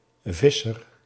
Visser (Dutch pronunciation: [ˈvɪ.sər]
Nl-Visser.ogg.mp3